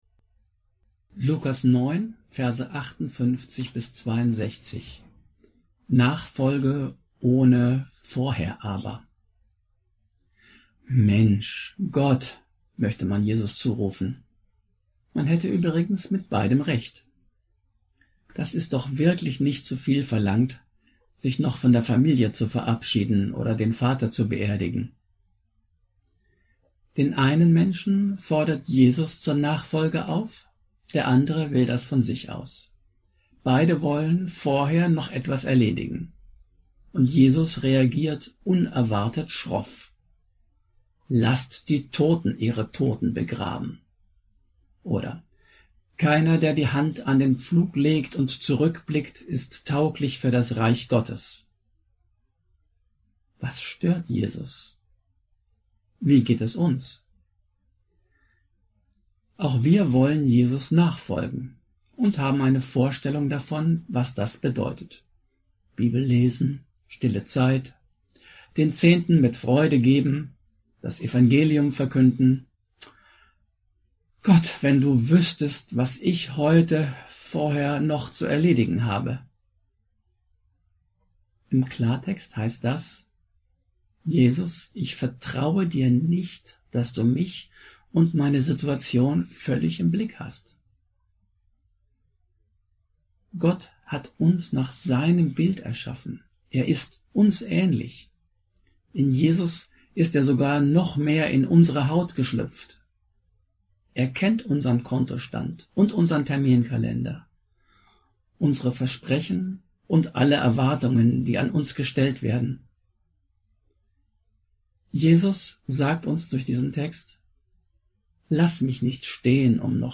Andacht